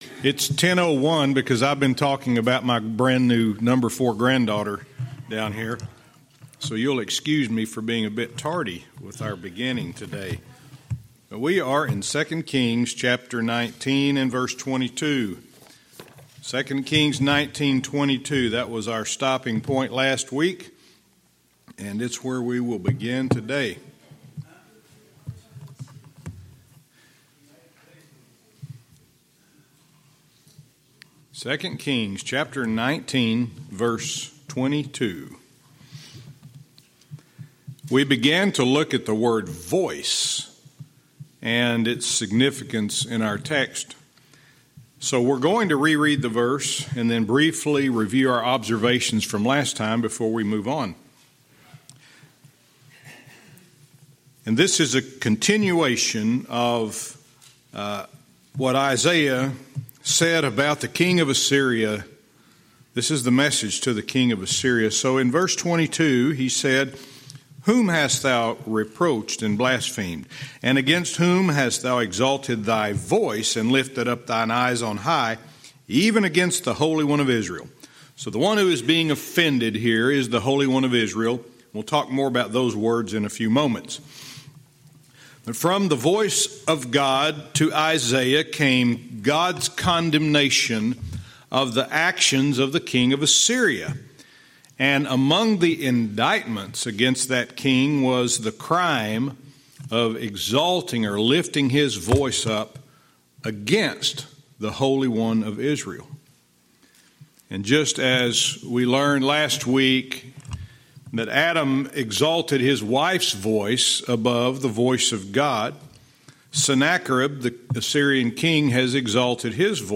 Verse by verse teaching - 2 Kings 19:22(cont)